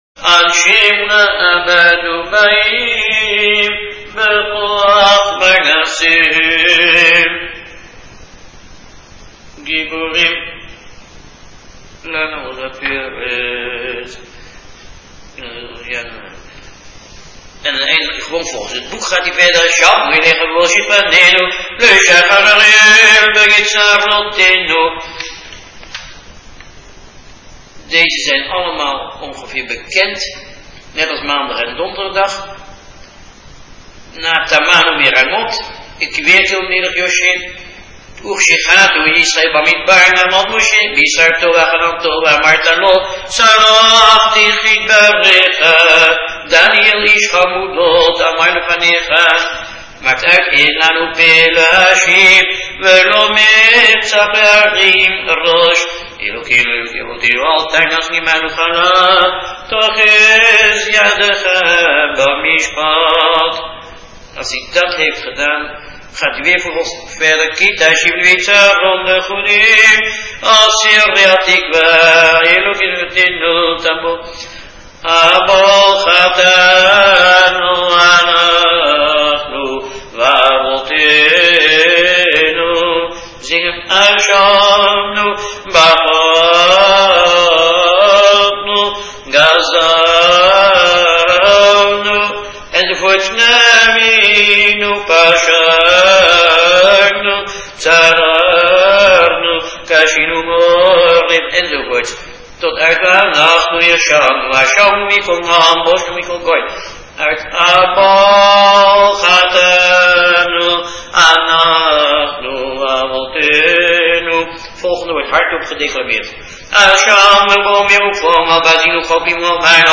·         Selichot part, taken from Selichot & RH/Kippur.
Tape 12-Selichot.mp3